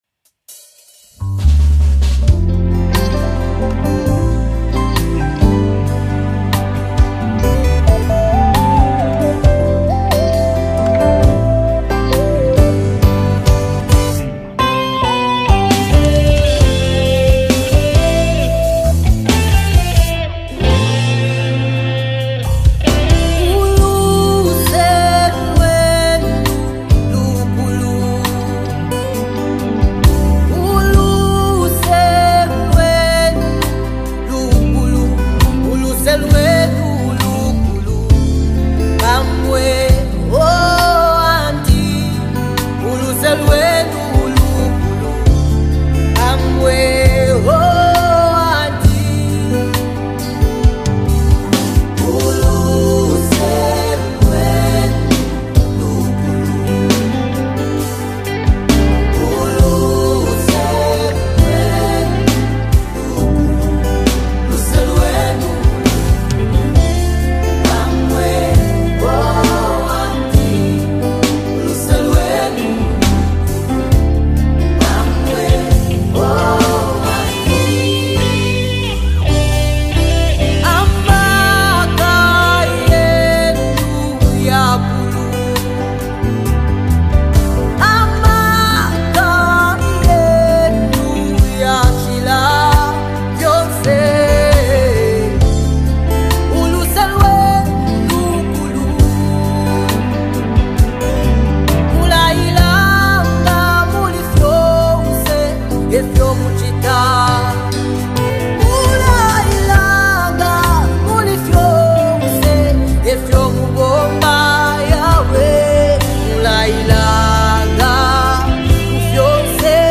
gospel
" is a soulful prayer for mercy.
create an atmosphere of deep worship.